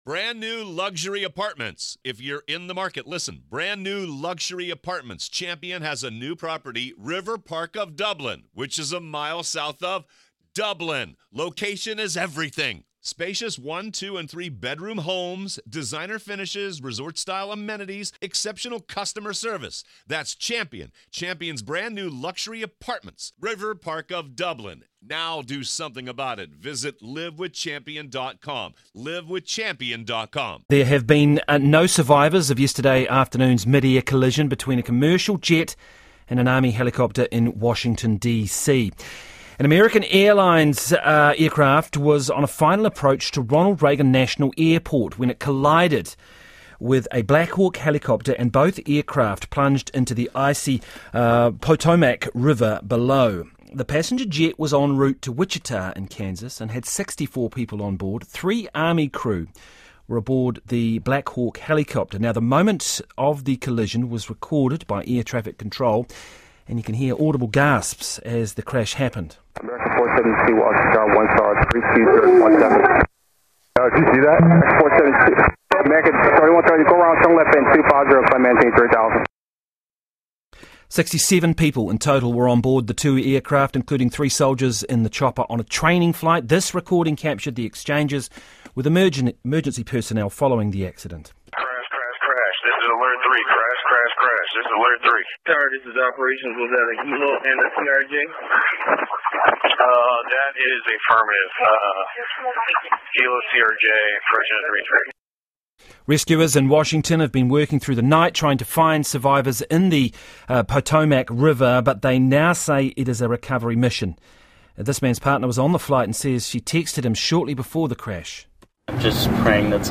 live report for Radio New Zealand's "Morning Report"